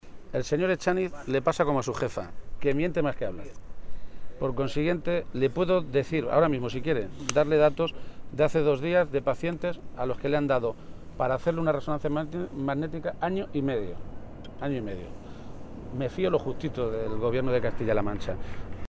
García-Page se pronunciaba de esta manera esta mañana, en Toledo, a preguntas de los medios de comunicación, e incidía en que “no hace falta nada más que darse una vuelta por cualquier centro de salud, o ver las urgencias colapsadas en cualquier Hospital de la región, para darse cuenta de cuál es la realidad de la Sanidad en Castilla-La Mancha”.
Cortes de audio de la rueda de prensa